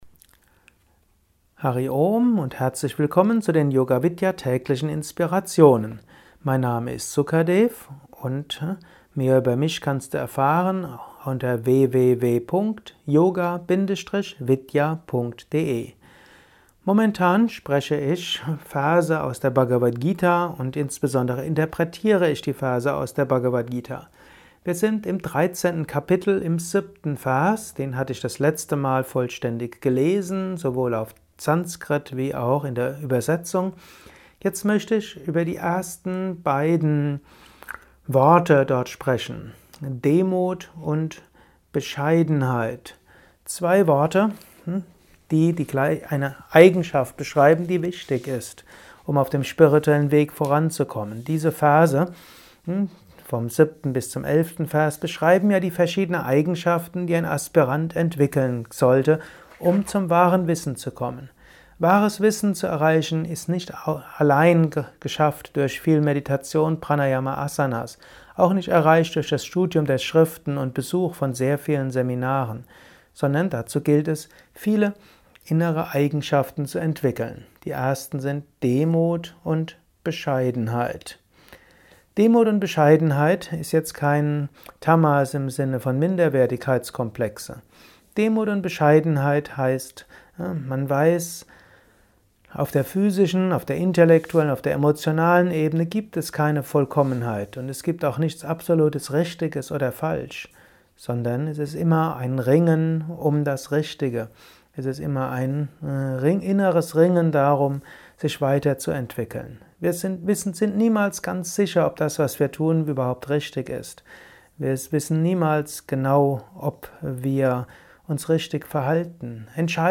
Kurzvorträge
Dies ist ein kurzer Kommentar als Inspiration für den heutigen